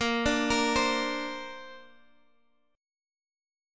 Bbadd9 chord